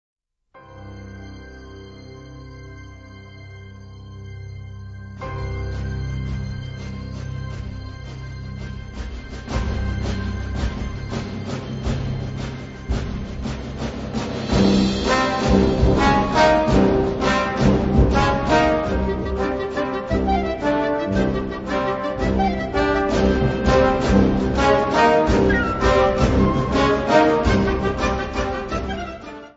Besetzung Ha (Blasorchester); Flt (Flöte)
Besetzungsart/Infos Solo f. 1Flt